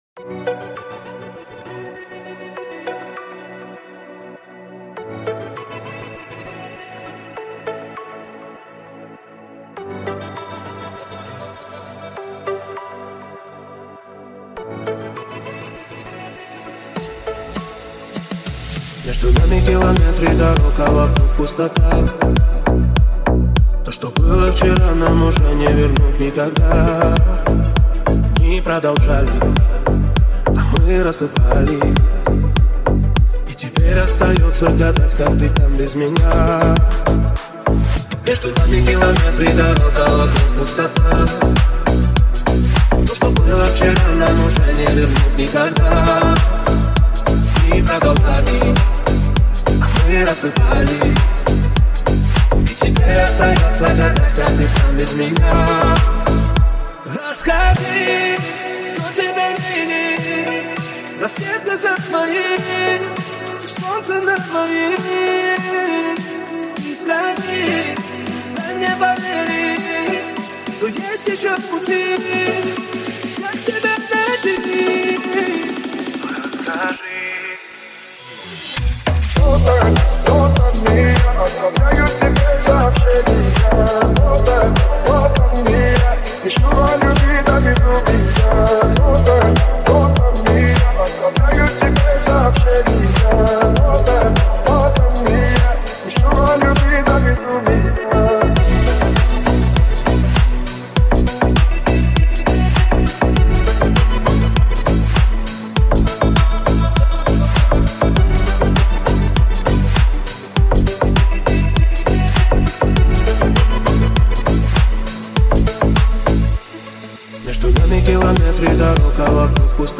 клубные ремиксы